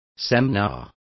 Complete with pronunciation of the translation of seminars.